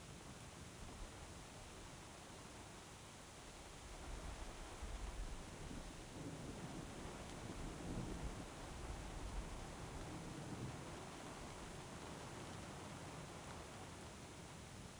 sfx_amb_combat_border.ogg